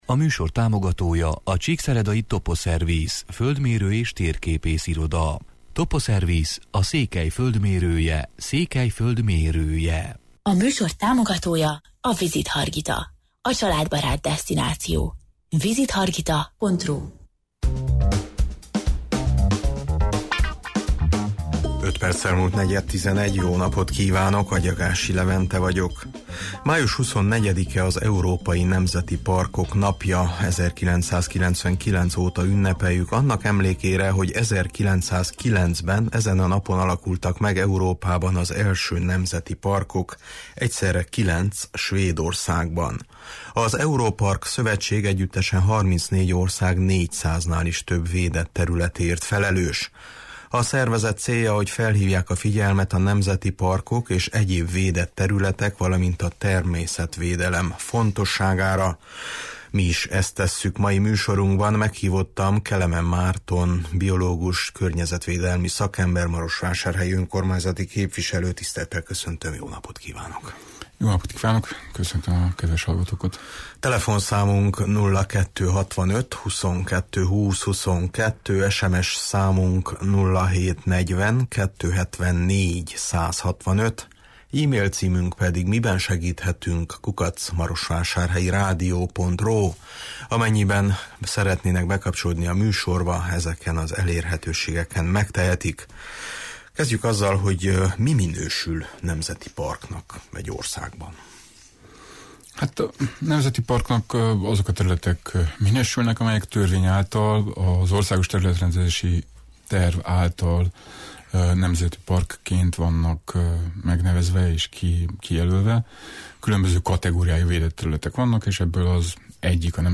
Meghívottam Kelemen Márton környezetvédelmi szakember, marosvásárhelyi önkormányzati képviselő.